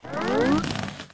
plantGrow.ogg